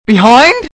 The book writing skill was more or less useless, side quests were often too well hidden from view (which was especially bad when most of them were time-limited), the accessories that gave you items were a bit in-your-face, there was no in-game mini-map, the invisible random encounters and lack of fast travel didn’t age well, and the voice acting…